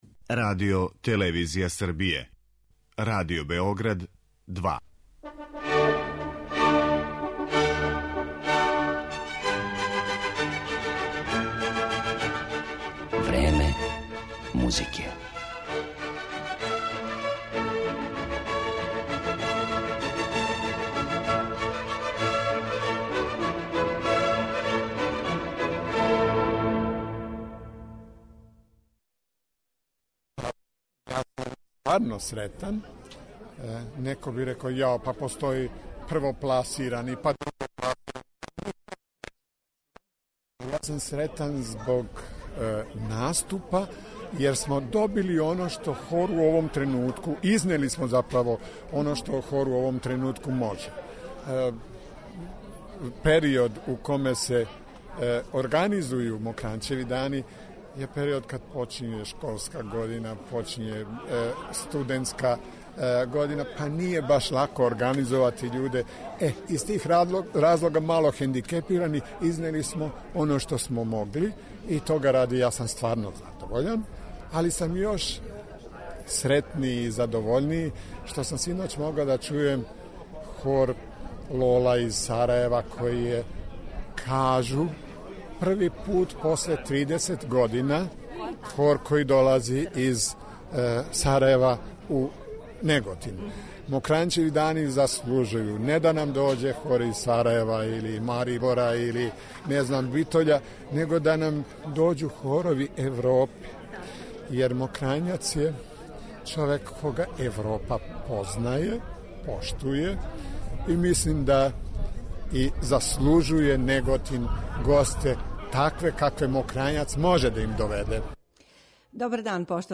Емисија се емитује из Неготина, у коме се одржавају 52. Мокрањчеви дани.